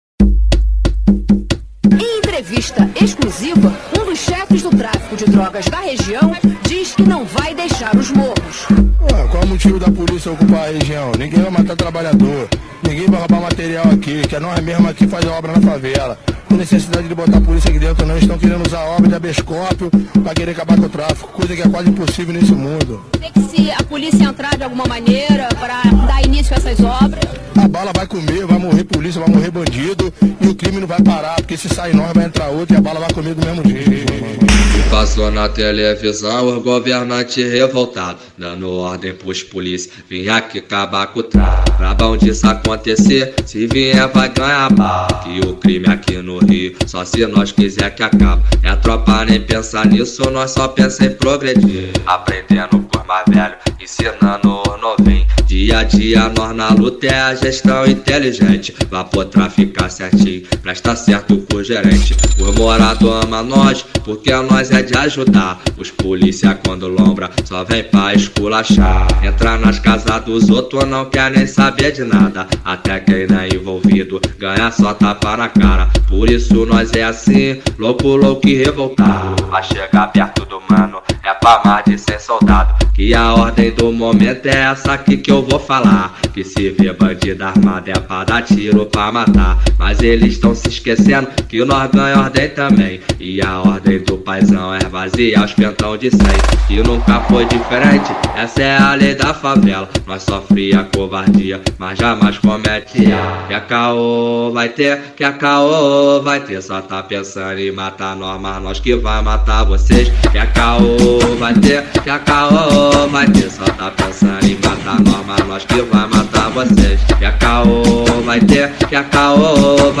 2024-11-30 08:44:25 Gênero: Funk Views